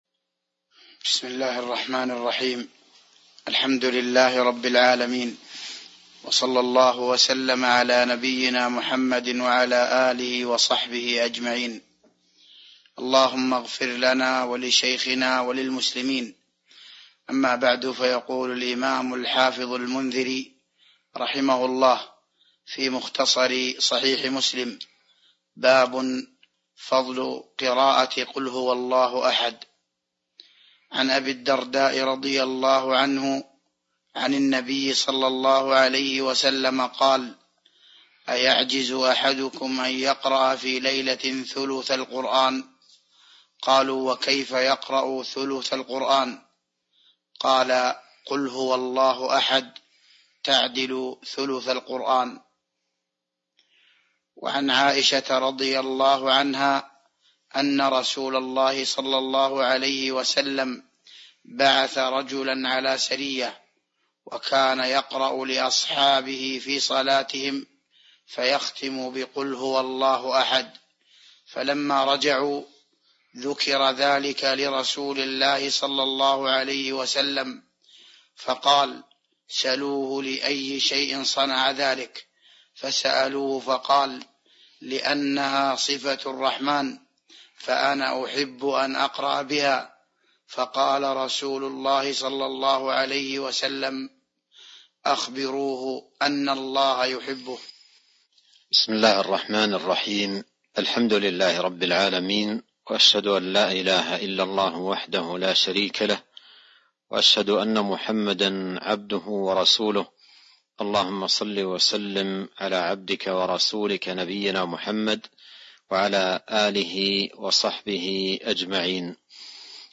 تاريخ النشر ٦ رمضان ١٤٤٢ هـ المكان: المسجد النبوي الشيخ: فضيلة الشيخ عبد الرزاق بن عبد المحسن البدر فضيلة الشيخ عبد الرزاق بن عبد المحسن البدر باب فضل قراءة قل هو الله أحد (06)